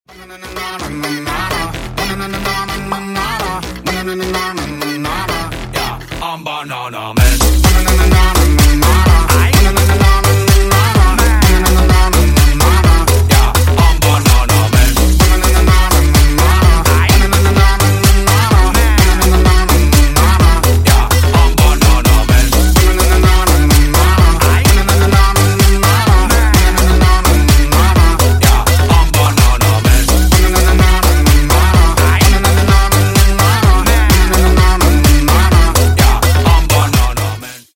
Весёлые Рингтоны
Танцевальные Рингтоны